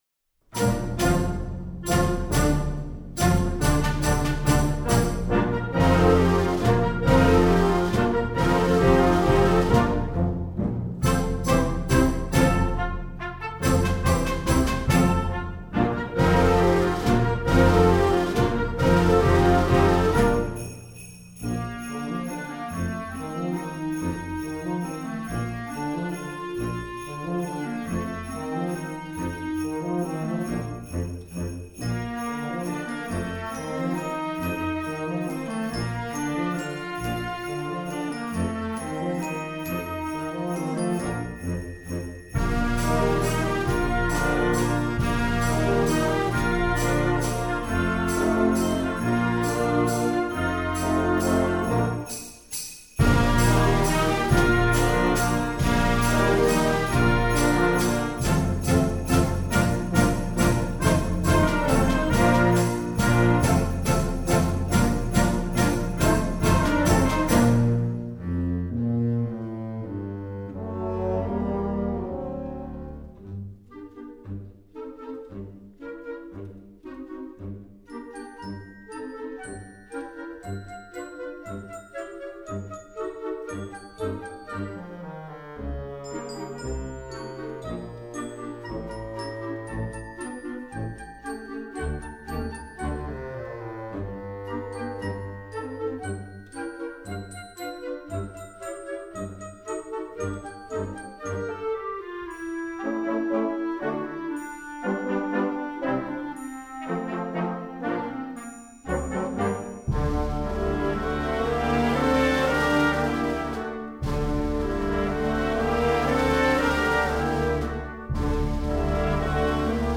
Instrumentation: concert band
masterwork arrangement, sacred, secular